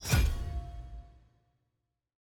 sfx-loot-button-circlegold-click.ogg